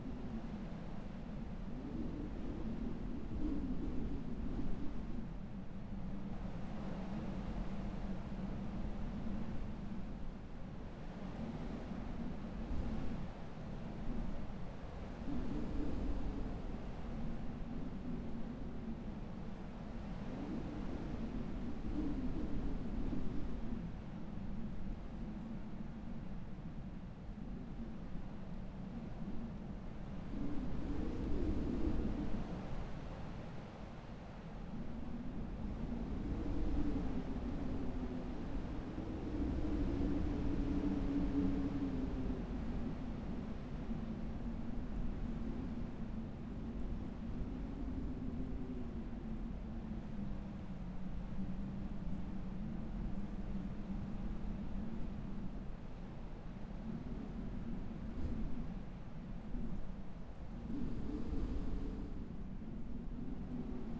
pgs/Assets/Audio/Sci-Fi Sounds/Hum and Ambience/Wind Loop 2.wav at master
Wind Loop 2.wav